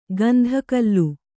♪ gandha kallu